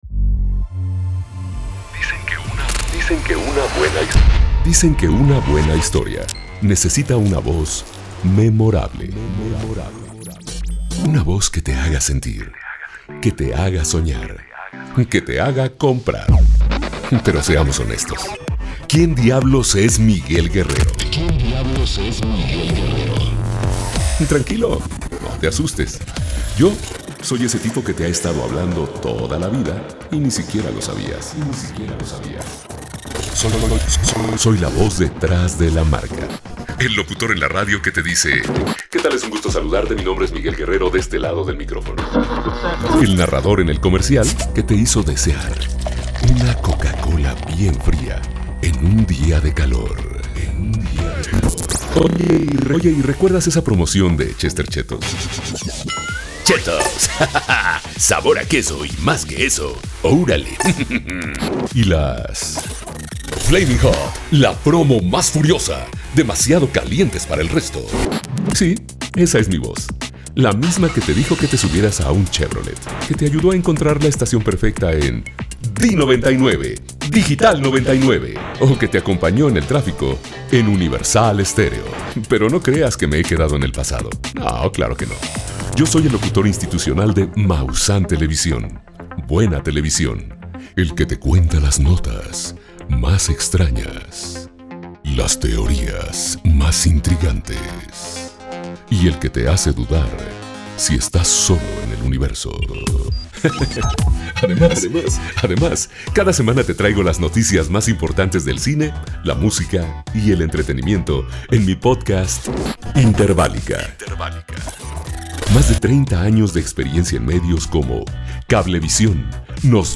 Escuchar este demo reel (MP3)